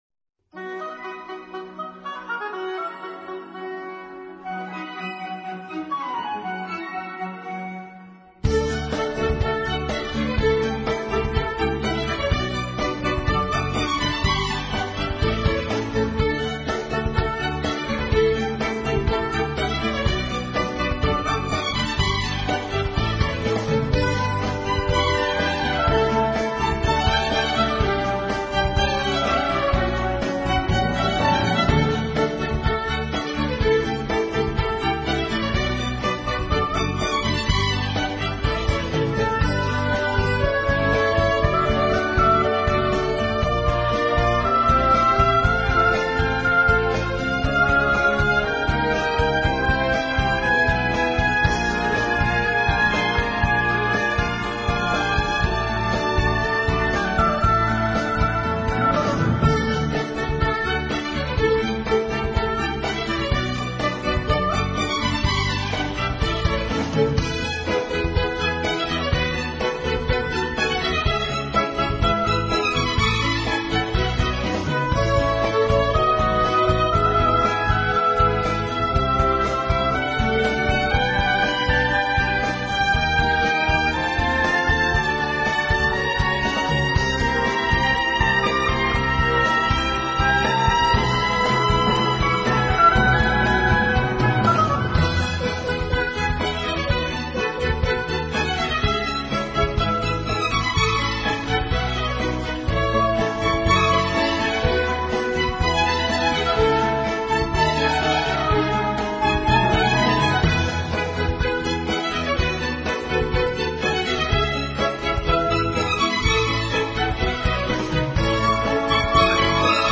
这就是古典元素（Oboe,Flauto,Corno,Archi,Pianoforte）
和现代元素（Batteria, Chitarra Basso,Synth）如何在作品当中相互融合的